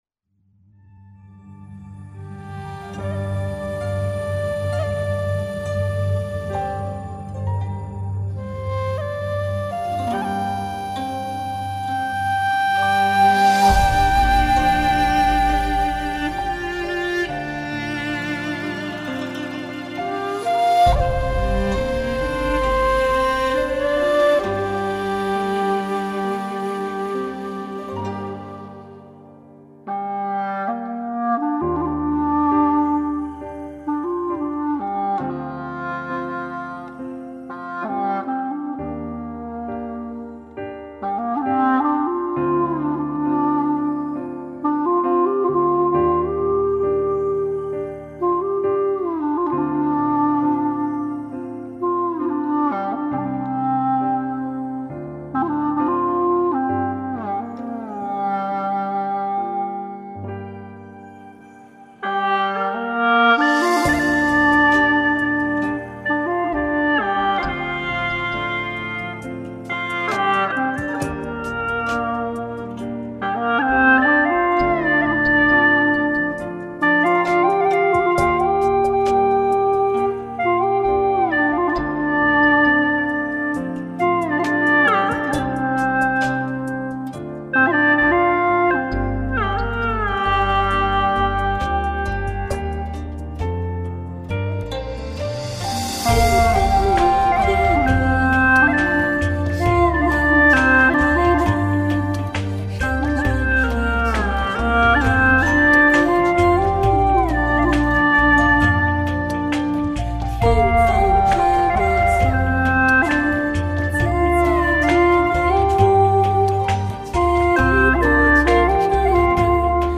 调式 : 降B 曲类 : 古风